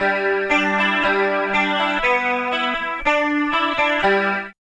78 GTR 3  -R.wav